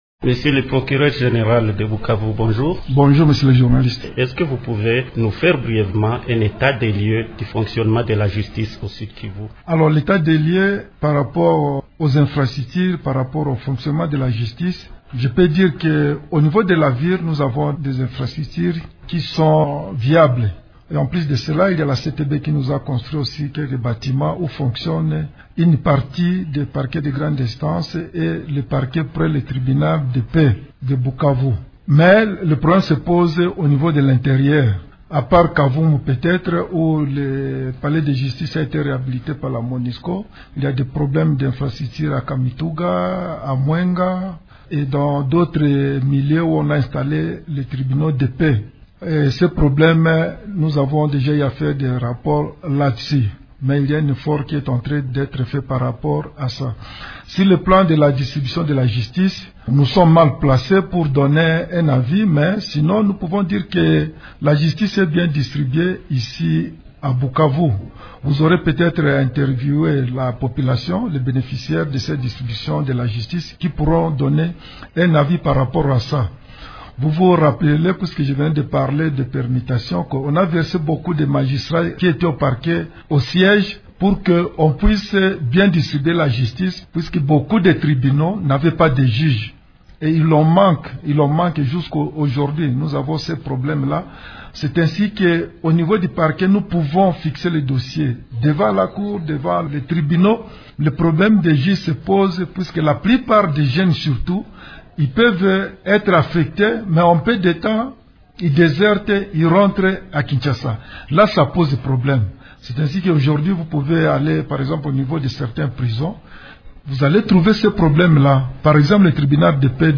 Invité de Radio Okapi mardi 10 janvier, il déplore néanmoins la carence de juges dans certains endroits comme le tribunal de paix de Kabare et le tribunal de grande instance de Kamituga.